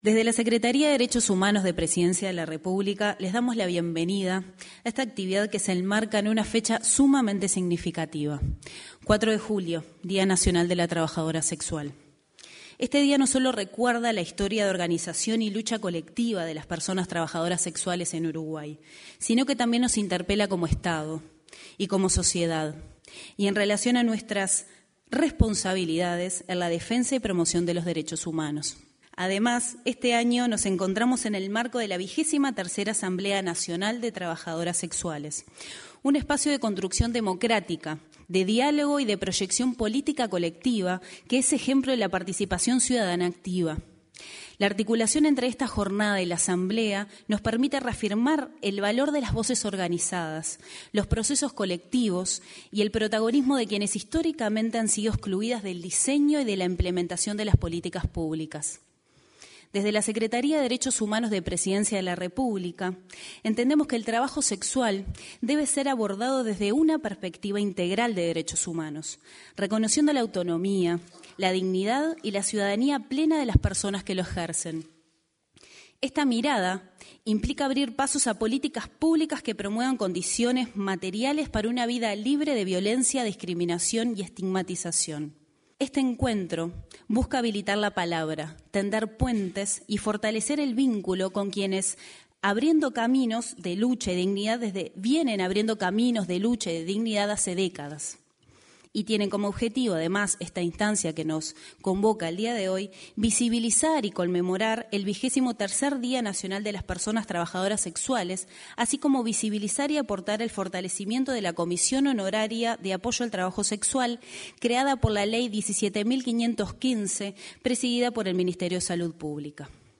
Mesa Trabajo Sexual y Derechos Humanos 22/07/2025 Compartir Facebook X Copiar enlace WhatsApp LinkedIn La titular de la Secretaría de Derechos Humanos de Presidencia, Collette Spinetti, y la senadora Bettiana Díaz, integrante de la Comisión de Derechos Humanos de la Cámara Alta, realizaron la apertura de la mesa de reflexión sobre trabajo sexual y derechos humanos, en la Torre Ejecutiva.